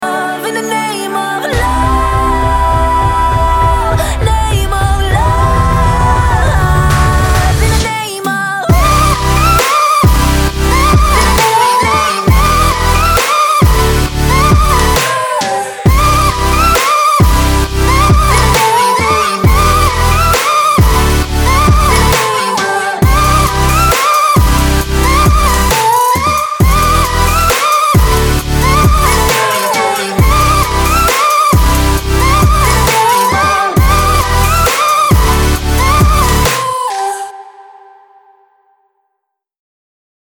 • Качество: 320, Stereo
громкие
женский вокал
dance
Electronic
future bass